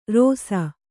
♪ rōsa